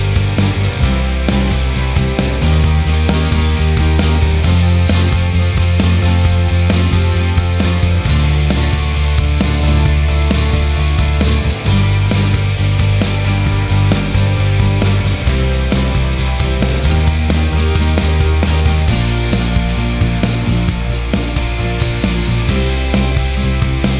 Minus Lead Guitars Duets 4:40 Buy £1.50